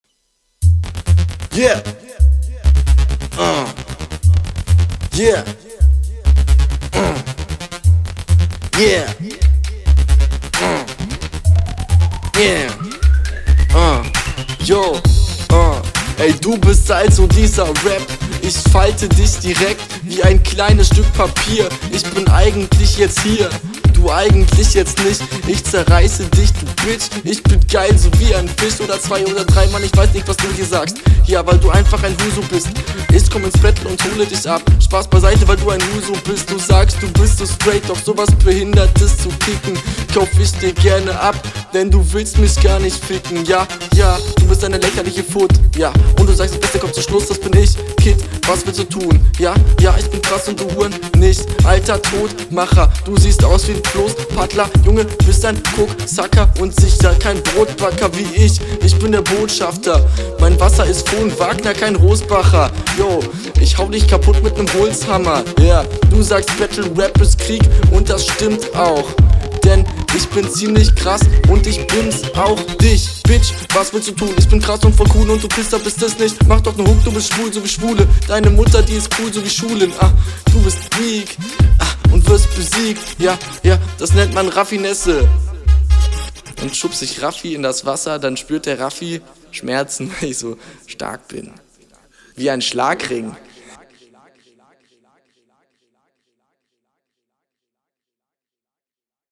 Okay kickst hier wohl nen 5min Freetype, jedenfalls klingt es so.
stimme war in deinen anderen runden besser gemischt. verarschst deinen gegner aber sehr schön. ist …